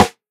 SNARE_U_IN_TROUBLE.wav